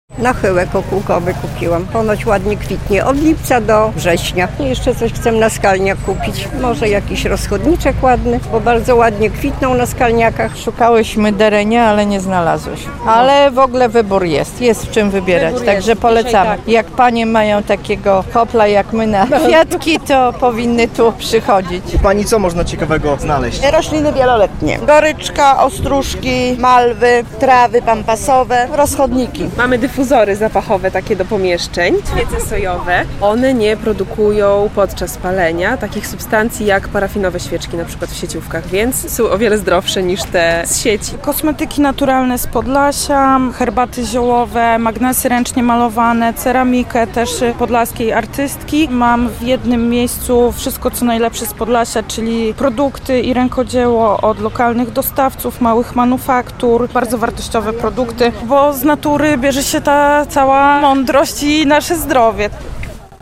Na placu przed Teatrem Dramatycznym w Białymstoku trwa Jarmark Kwiatów i Rękodzieła.
relacja